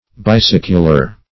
Bicycular \Bi*cyc"u*lar\, a. Relating to bicycling.